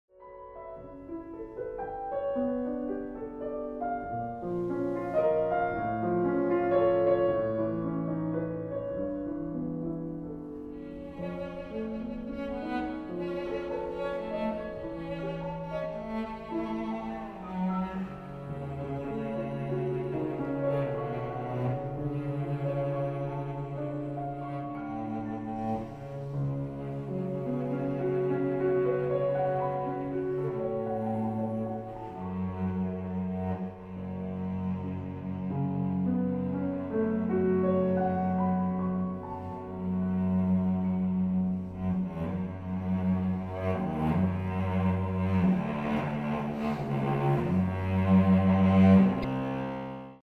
大提琴
鋼琴
使用骨董真空管麥克風錄音，並以自製的真空管混音台混音